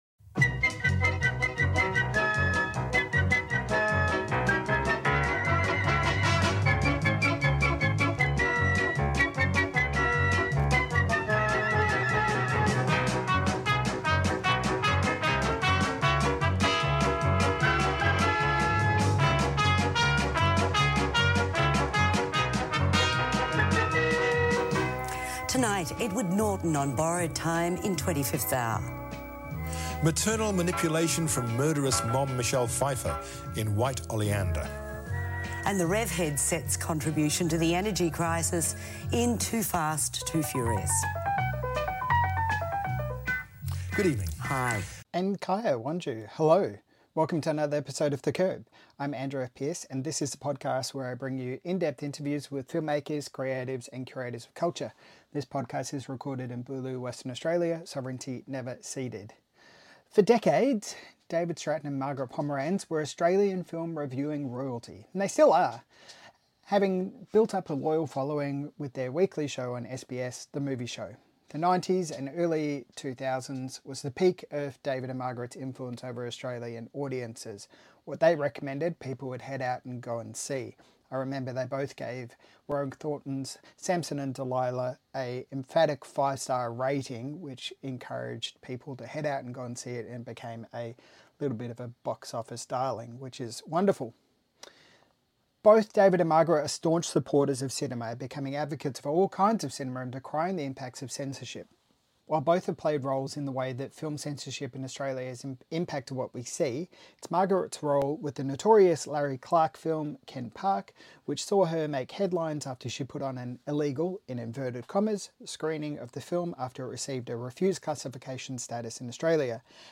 In the following interview